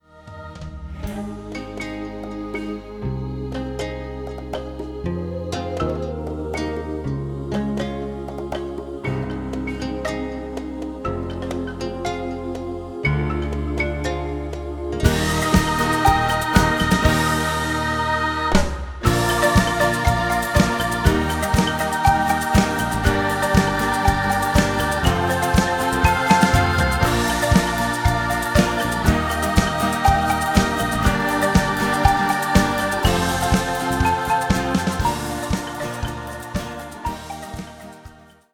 Musically, the show has lots of African rhythms and instrumentation.
06 Ready Now (Backing Only Snippet) Tuesday, 11 April 2017 04:20:22